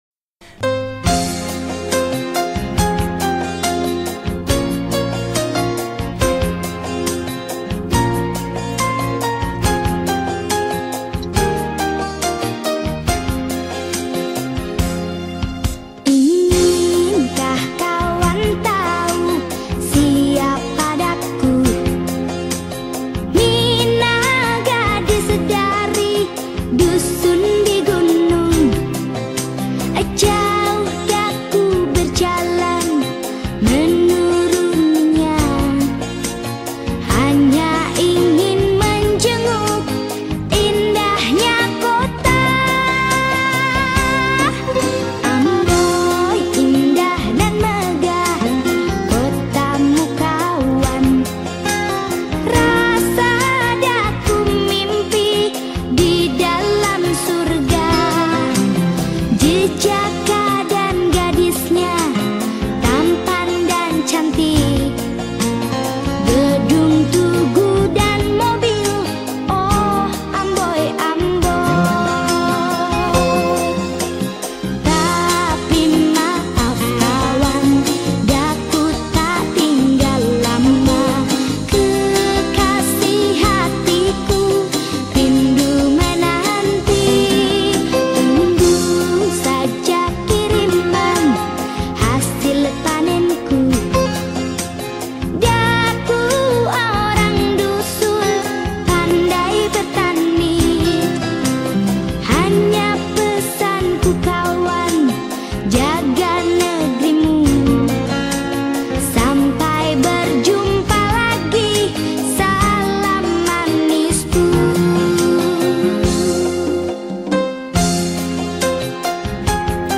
Indonesian Song
Skor Angklung